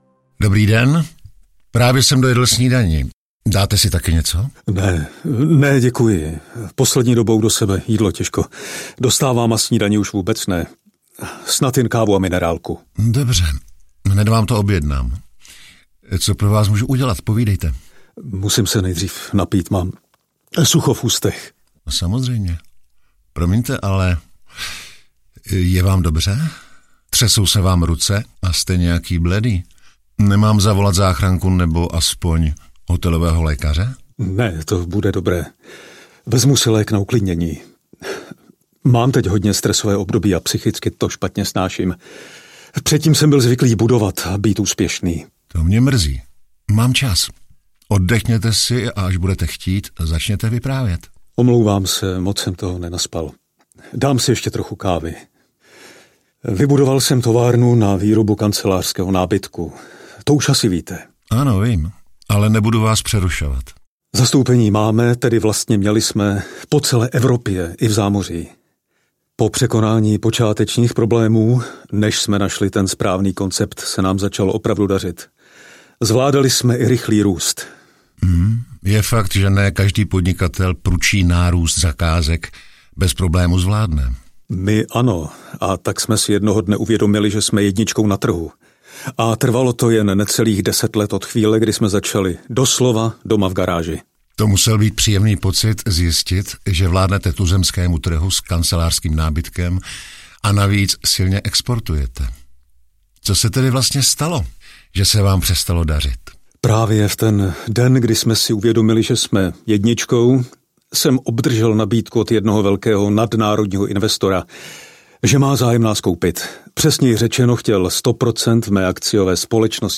Audio knihaKrotitel rizik podnikání zasahuje: Výroba nábytku
Ukázka z knihy